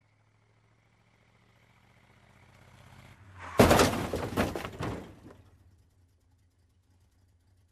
Шум аварии: машина сбивает пешехода на трассе